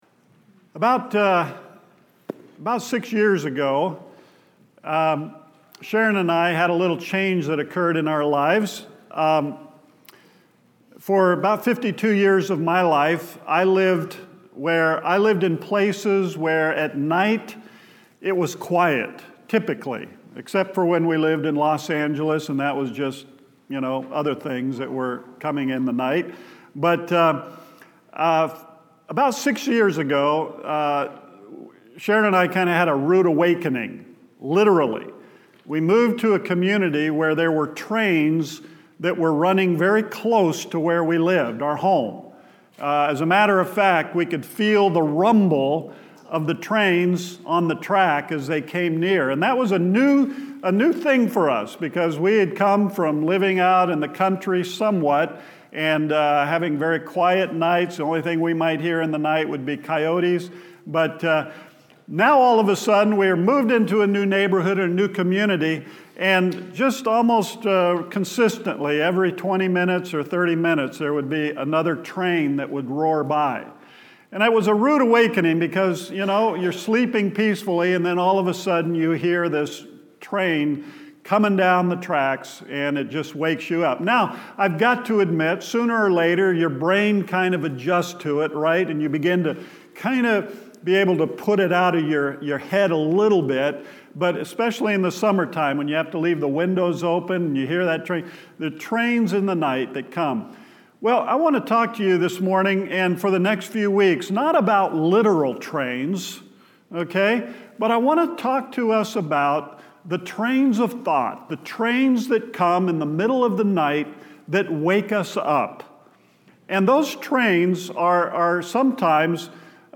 Central Baptist Church Sermons